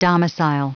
Prononciation du mot domicile en anglais (fichier audio)
Prononciation du mot : domicile